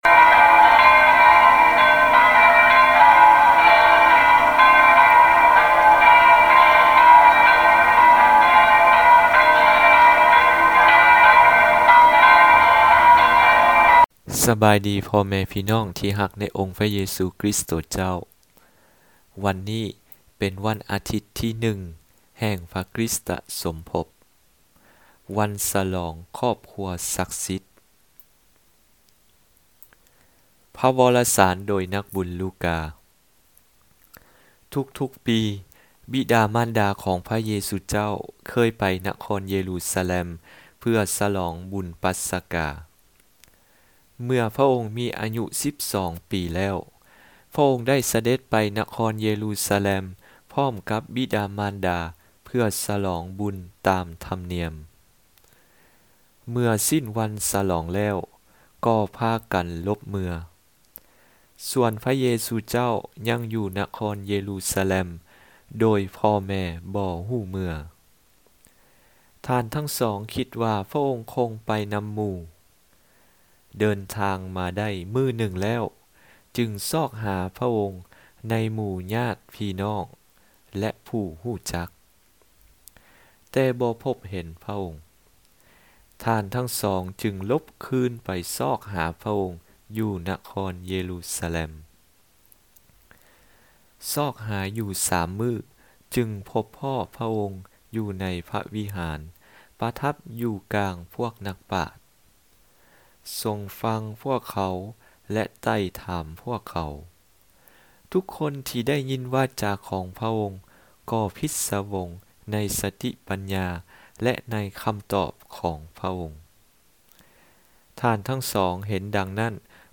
Homelie_Sainte_Famille.mp3